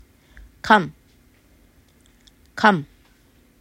カㇺ　　　　　　　kam        肉